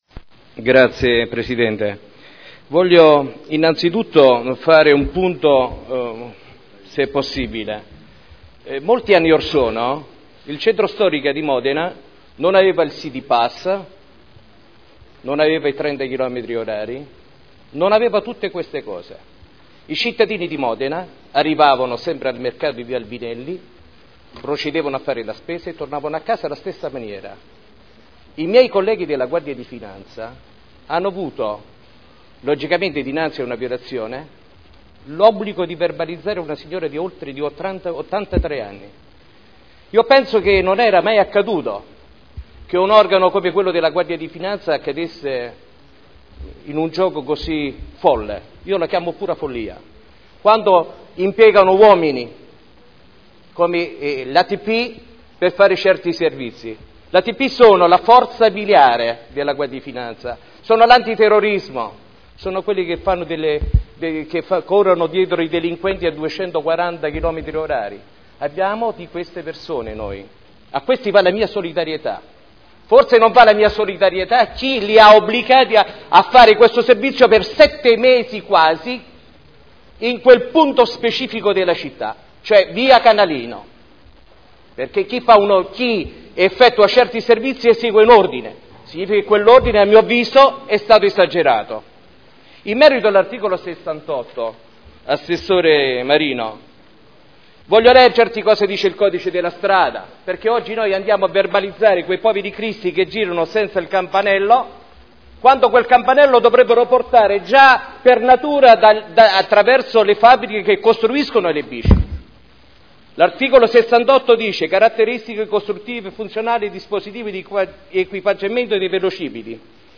Maurizio Dori — Sito Audio Consiglio Comunale